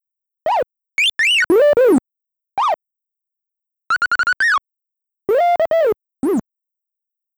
ANNCheering1.wav